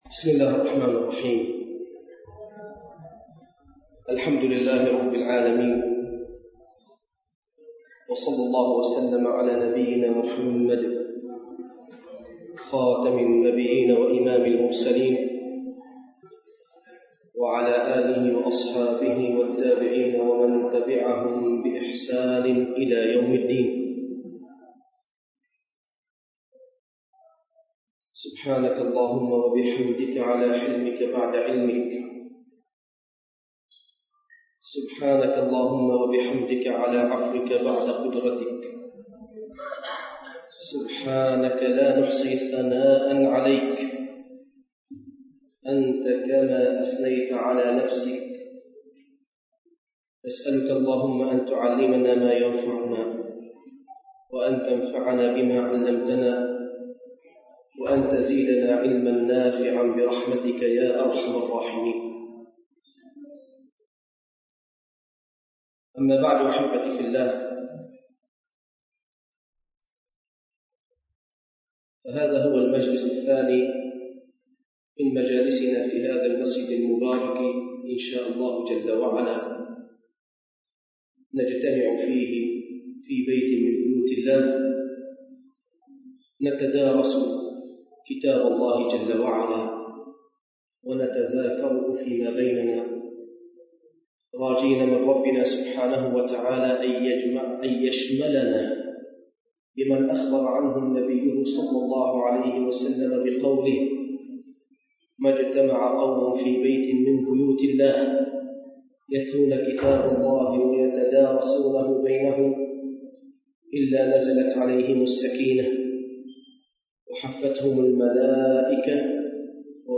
المكان: مسجد القلمون الغربي